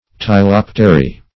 Search Result for " ptilopteri" : The Collaborative International Dictionary of English v.0.48: Ptilopteri \Pti*lop"te*ri\, n. pl.